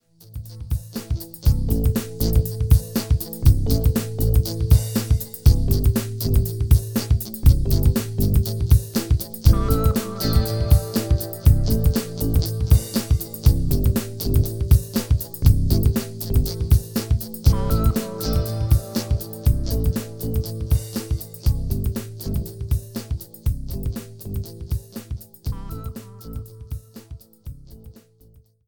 Audiorecording, Musik & Sounddesign